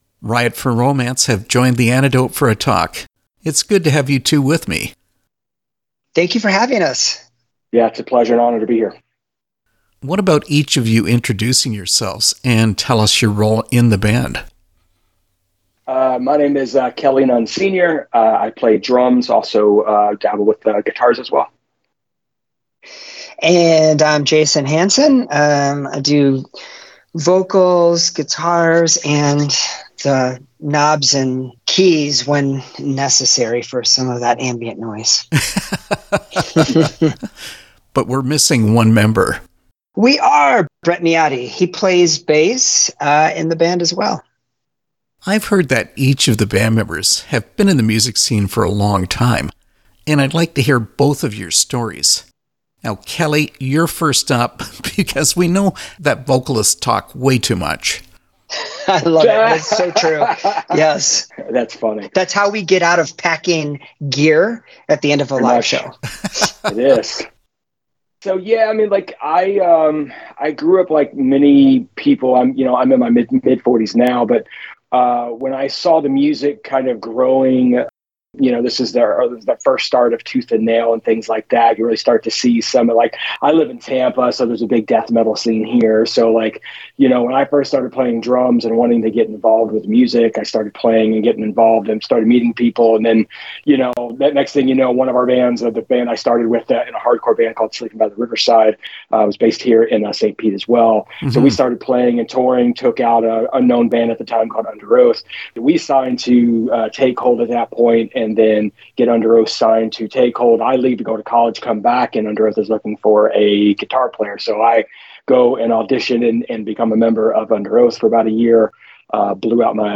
Interview with Riot For Romance
riot-for-romance-interview.mp3